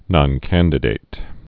(nŏn-kăndĭ-dāt, -dĭt)